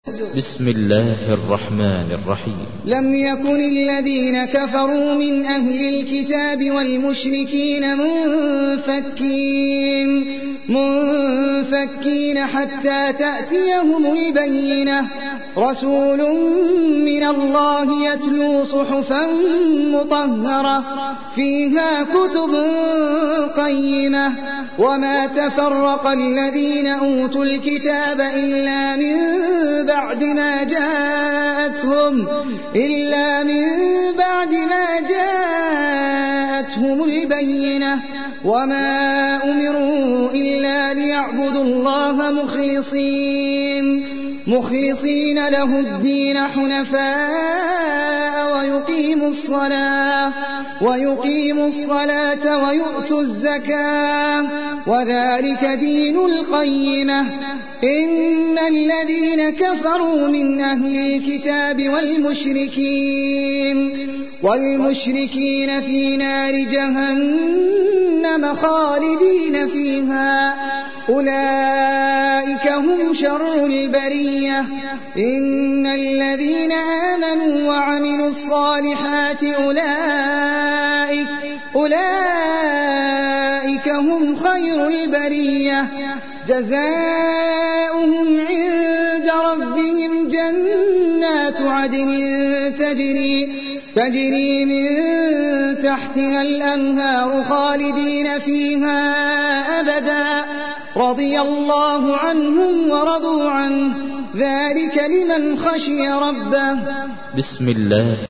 تحميل : 98. سورة البينة / القارئ احمد العجمي / القرآن الكريم / موقع يا حسين